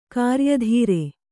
♪ kāryadhīre